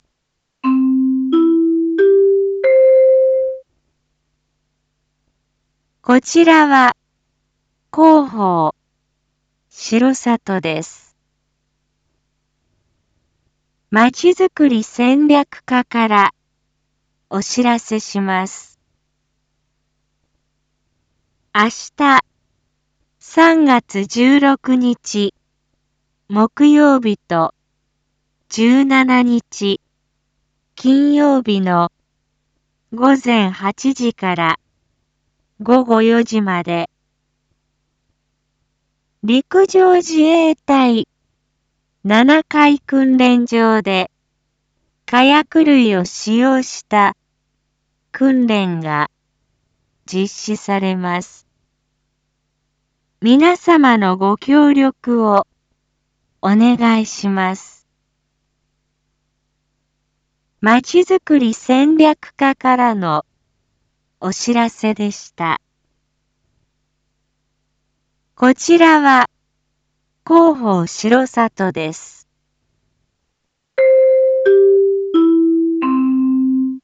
Back Home 一般放送情報 音声放送 再生 一般放送情報 登録日時：2023-03-15 19:01:18 タイトル：R5.3.15 19時放送分 インフォメーション：こちらは広報しろさとです。